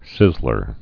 (sĭzlər)